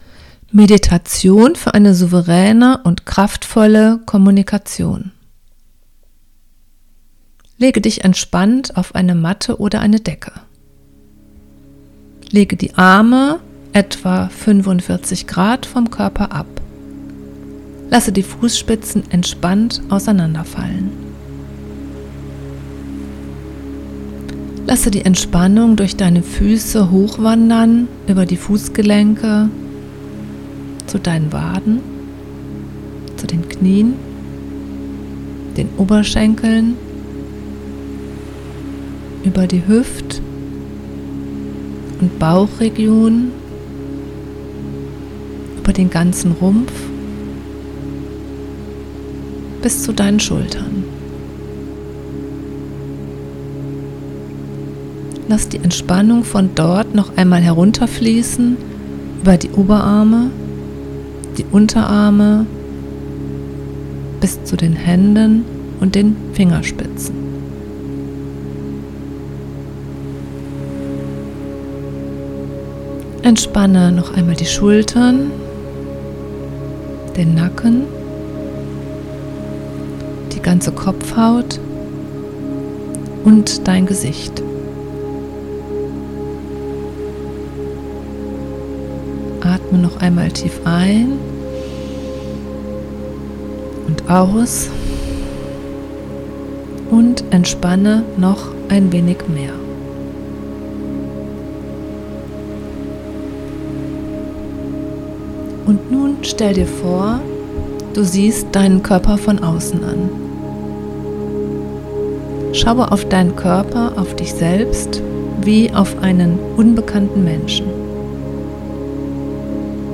Meditation-fuer-souveraene-Kommunikation.mp3